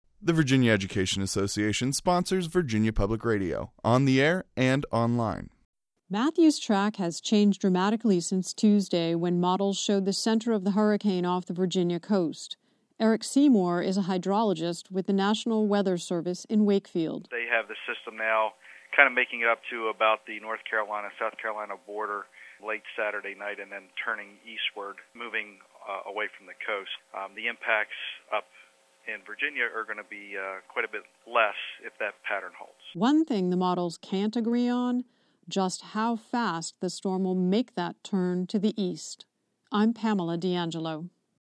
vpr-hurricane-matthew-update.mp3